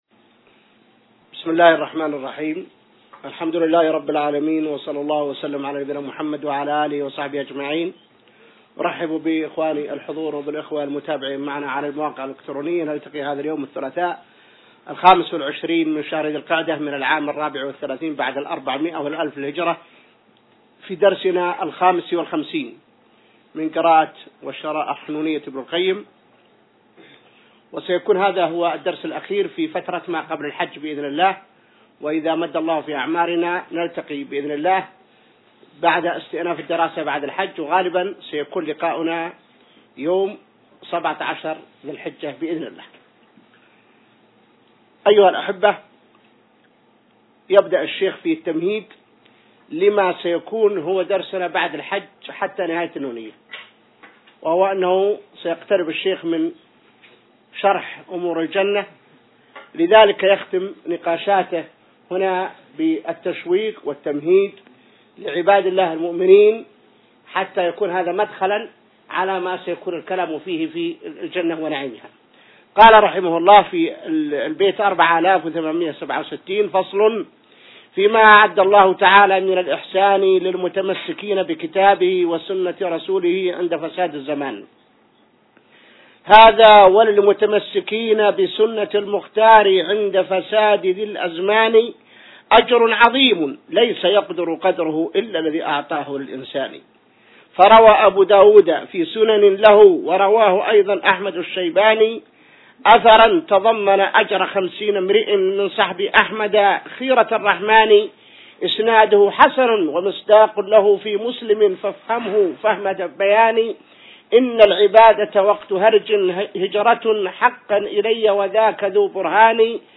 الدرس 55 من شرح نونية ابن القيم | موقع المسلم